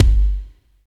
64 KICK 3.wav